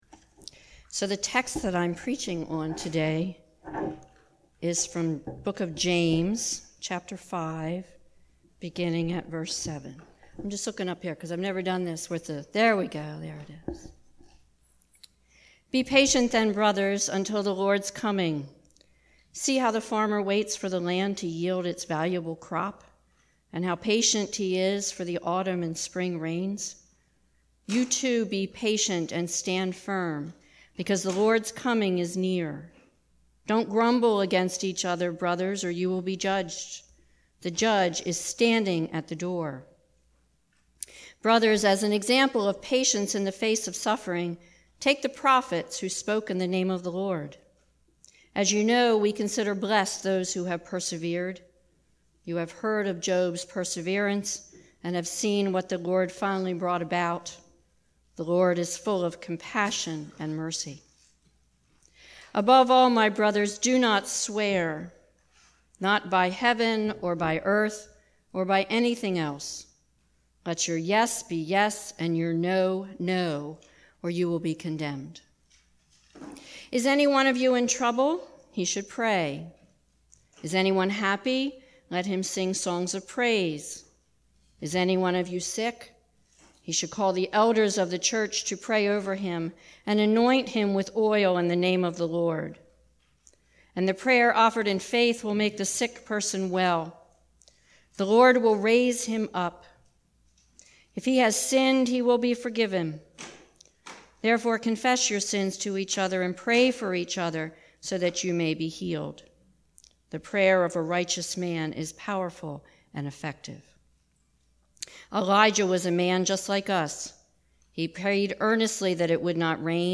2018 Patience and Power of Prayer Preacher